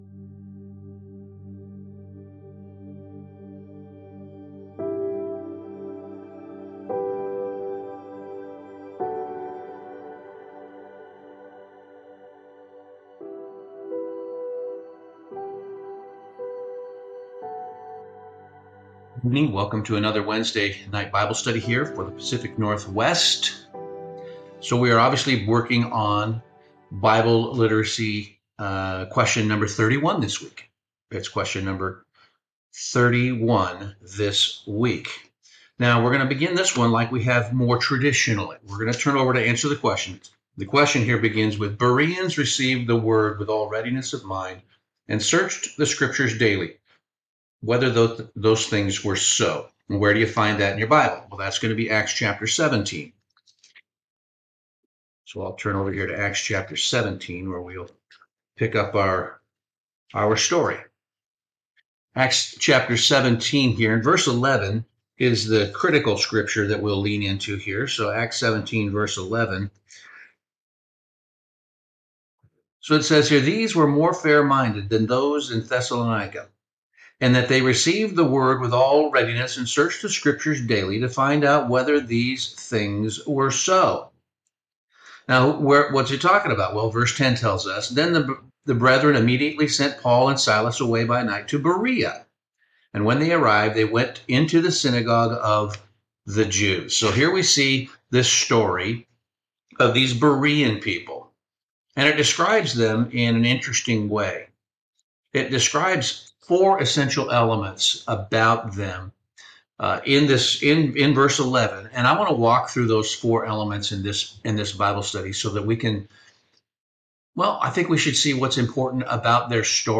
This Bible study examines why the Bereans were singled out for praise—not for enthusiasm or tradition, but for how they handled truth.